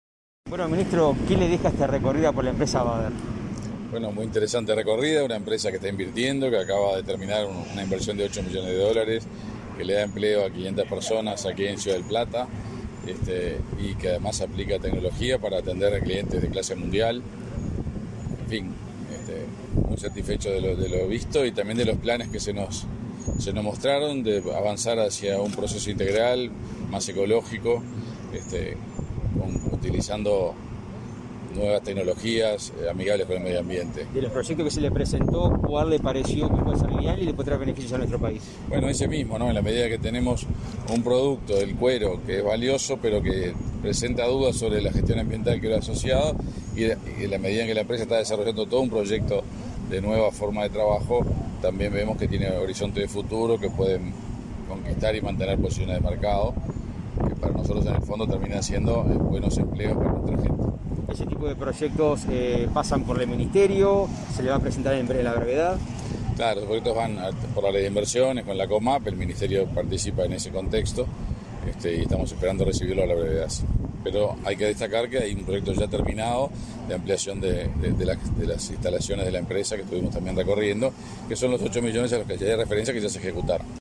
Declaraciones del ministro de Industria, Energía y Minería, Omar Paganini
Autoridades del Ministerio de Industria visitaron la planta de Bader en Ciudad del Plata, este 1 de diciembre.
Tras el recorrido, el ministro Paganini efectuó declaraciones a Comunicación Presidencial.